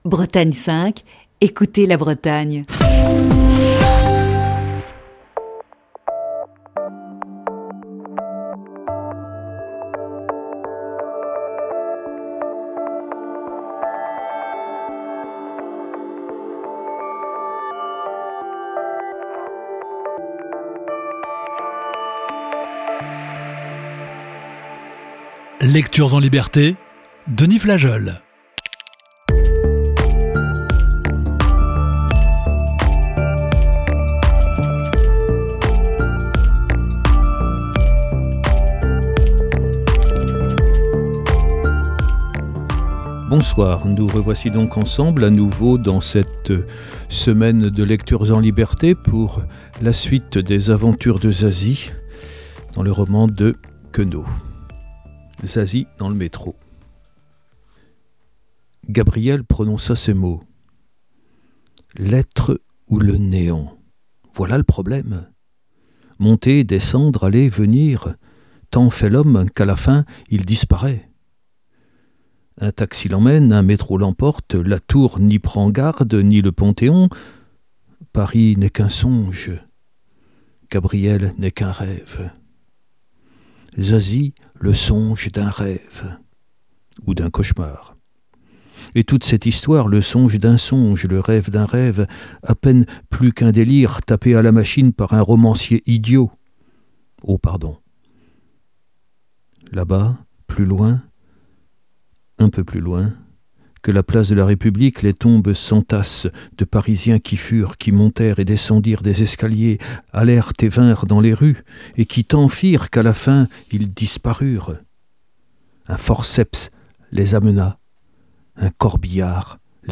Émission du 6 avril 2020.